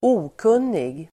Uttal: [²'o:kun:ig]